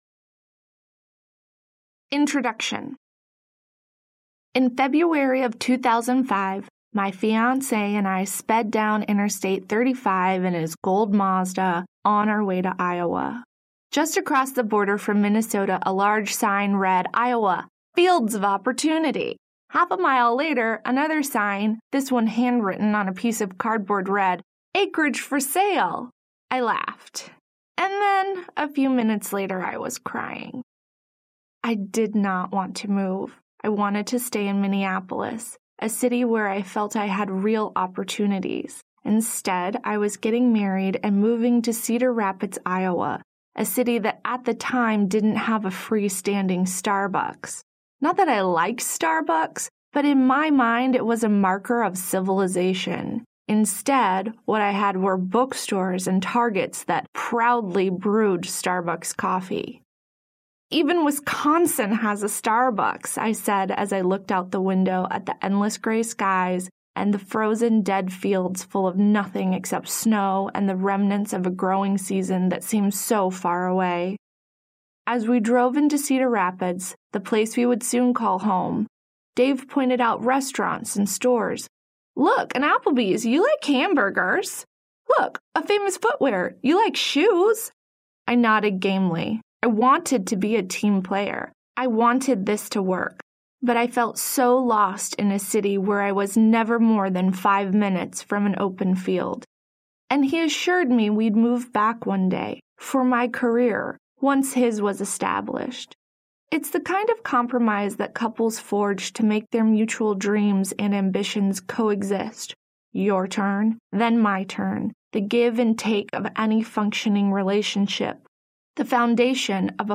God Land Audiobook
Narrator